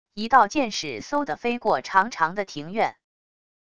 一道箭矢嗖的飞过长长的庭院wav音频